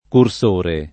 [ kur S1 re ]